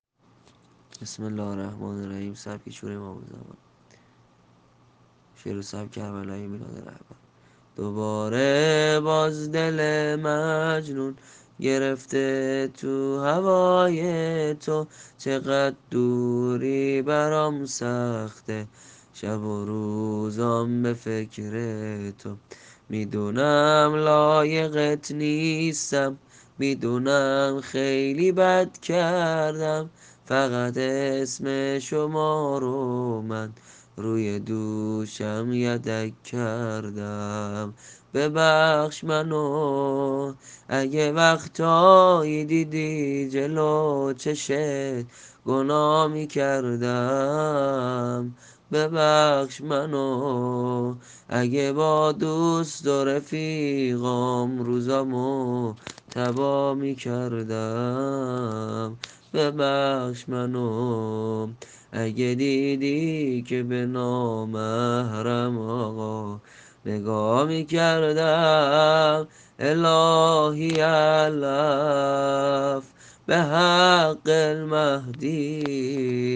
‌?✨شورامام زمانی✨?